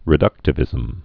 (rĭ-dŭktə-vĭzəm)